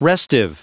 Prononciation du mot restive en anglais (fichier audio)
Prononciation du mot : restive